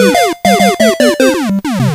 The music for failing at getting a reward